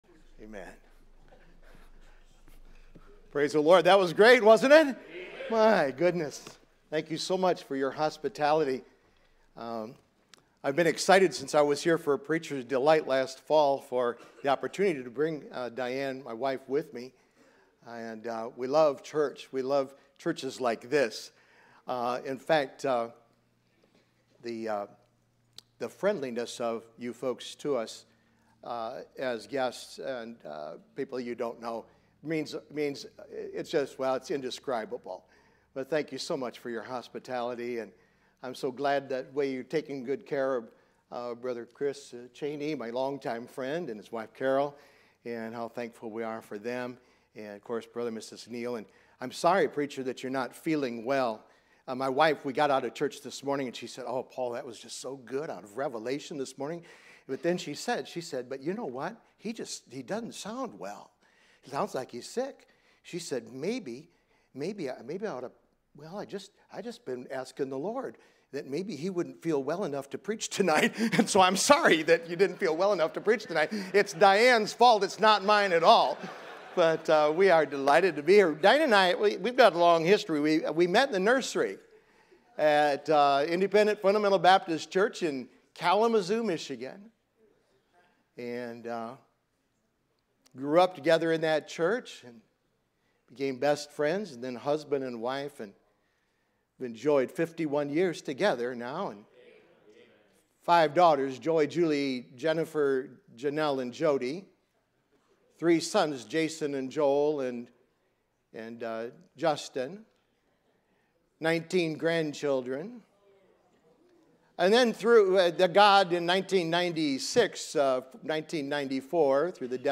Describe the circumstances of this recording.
Sermons from the pulpit of Immanuel Baptist Church in Jacksonville, FL